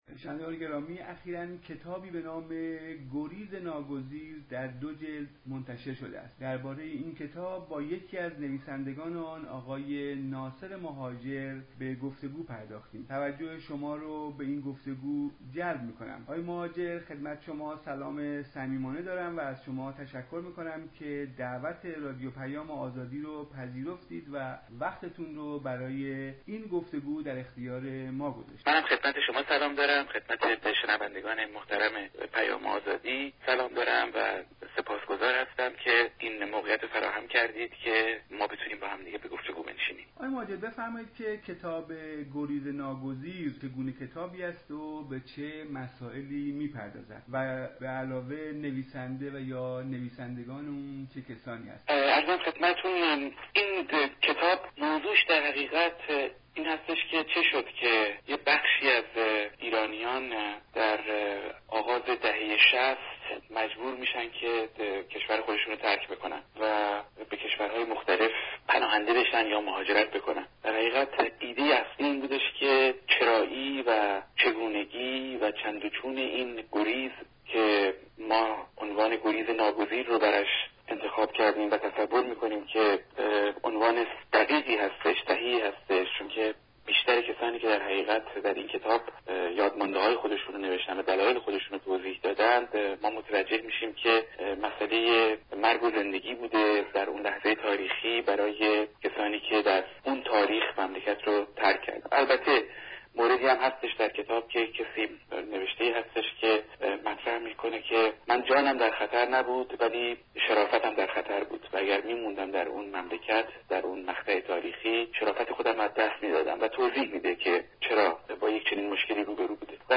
گفتگوی رادیوپیام آزادی